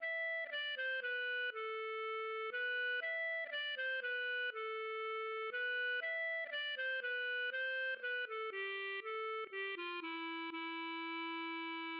LilyPond 🏰" } myMusic = { << \chords { \germanChords \set chordChanges=##t \set Staff.midiInstrument="acoustic guitar (nylon)" } \relative c'' { \time 3/4 \key c \major \tempo 4=120 \set Staff.midiInstrument="clarinet" e4 \grace d16